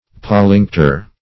Search Result for " pollinctor" : The Collaborative International Dictionary of English v.0.48: Pollinctor \Pol*linc"tor\, n. [L., fr. pollingere.]